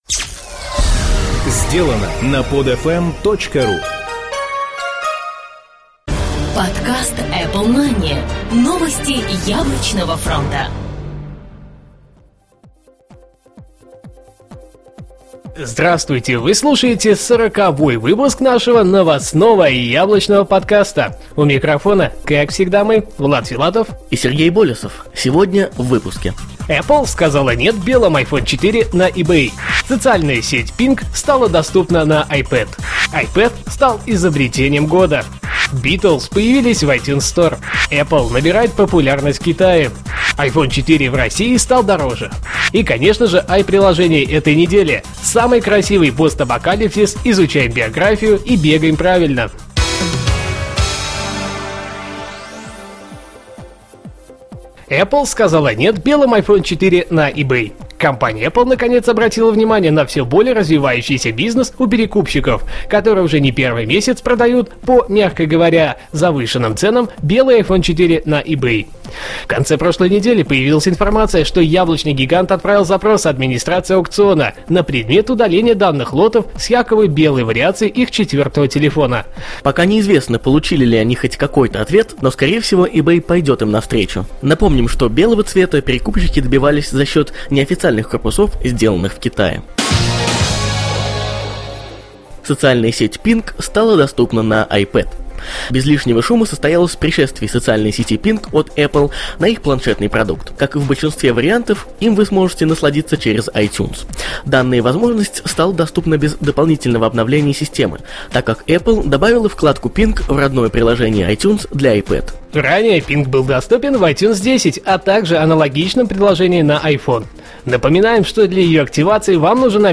"Apple Mania" - еженедельный новостной Apple подкаст
Жанр: новостной Apple-podcast
Битрейт аудио: 80-96, stereo